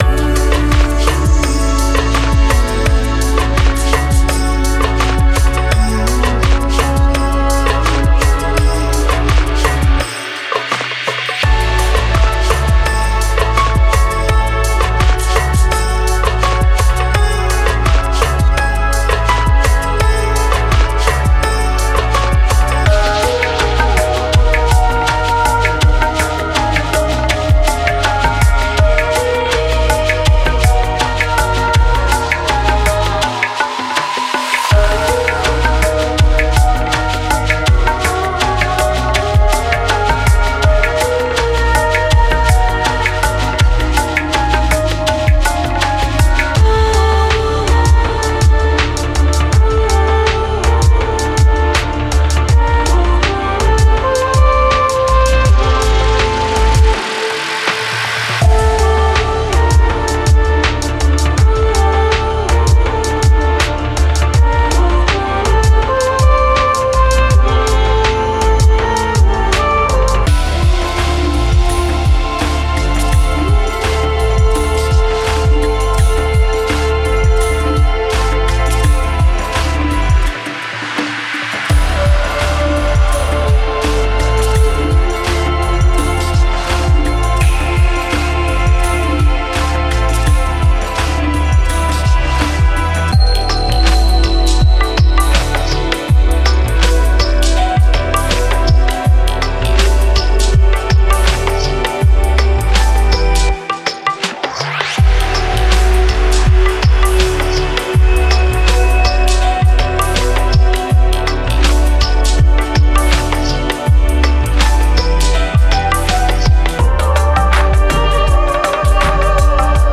Genre:Chillout
自然や古代の儀式から着想を得た、滑らかなアンビエントパッドと進化するテクスチャ
オーガニックなグルーヴと手演奏の質感を備えたパーカッションループ
ミックスに温かみと安定感をもたらす深みのあるベースライン
フルート、ベル、アンビエントシンセによるエーテル的なメロディ
10 Angelic Vocals